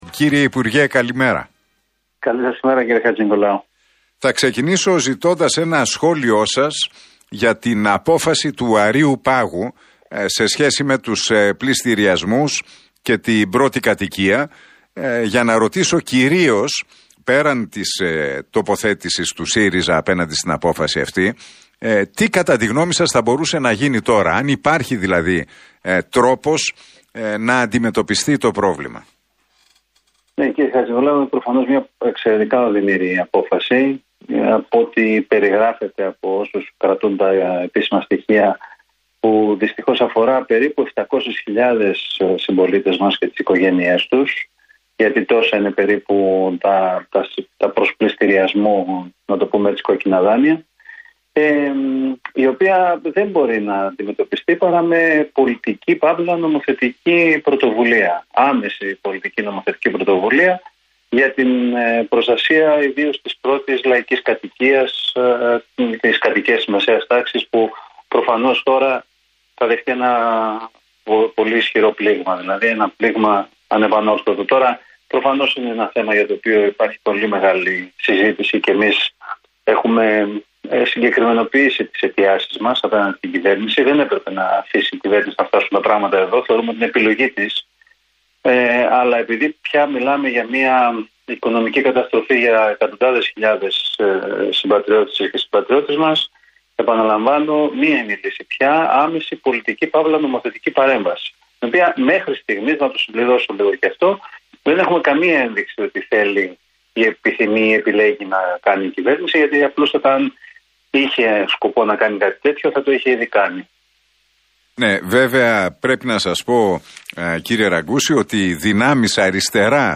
Ο κοινοβουλευτικός εκπρόσωπος του ΣΥΡΙΖΑ, Γιάννης Ραγκούσης δήλωσε στον Realfm 97,8 και στην εκπομπή του Νίκου Χατζηνικολάου πως «η απόφαση αποχής από τις